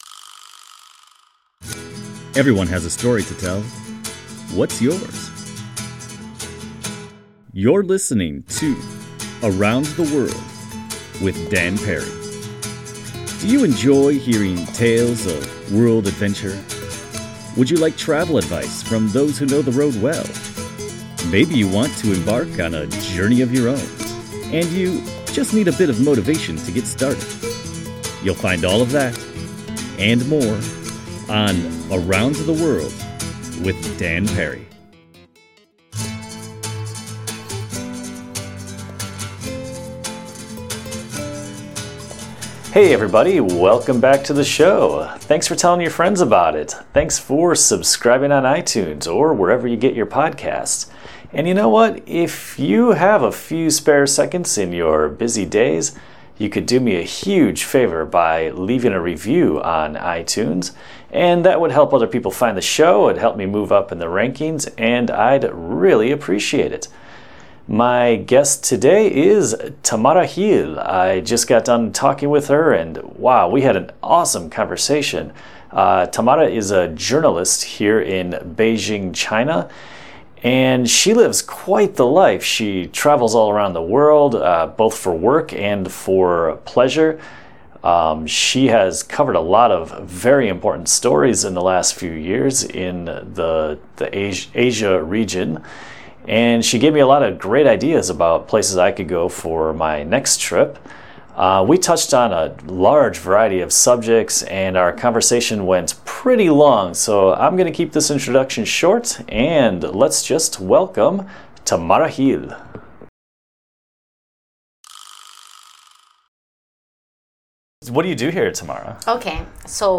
We had a great conversation about travel and the state of the world.